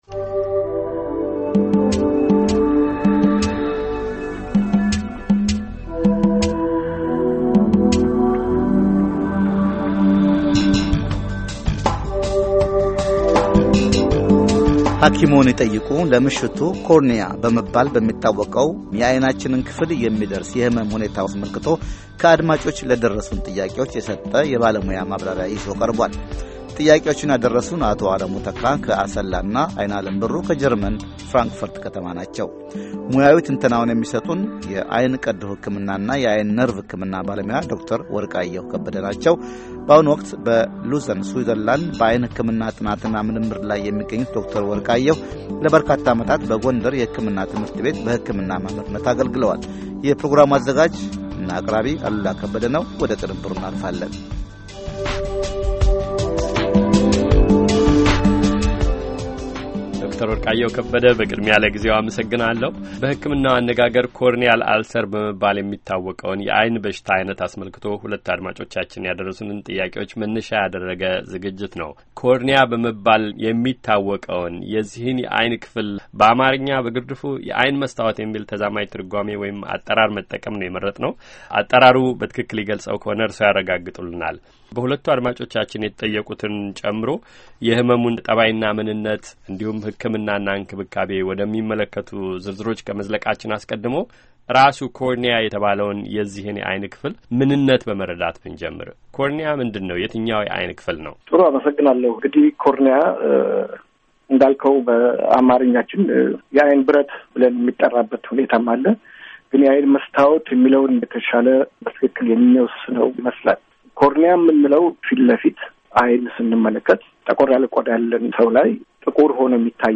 የቃለ ምልልሱን የመጀመሪያ ክፍል ቀጥሎ ያድምጡ